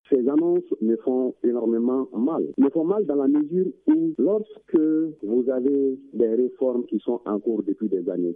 REACTION-SYNDICAT-GRABRIEL-TOURE.mp3